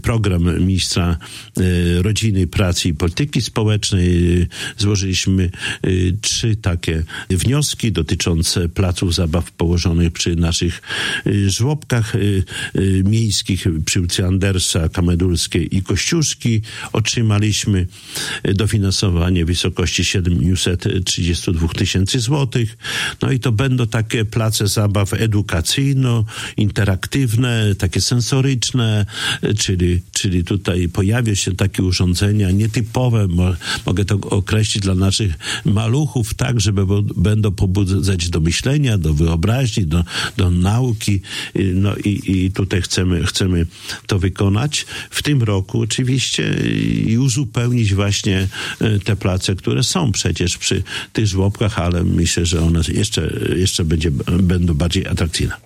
Jak poinformował w Radiu 5 Czesław Renkiewicz, prezydent Suwałk, nowe place zabaw pojawią się jeszcze w tym roku.